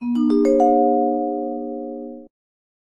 Index of /phonetones/unzipped/Sony/Xperia-XA1/notifications
whisper.ogg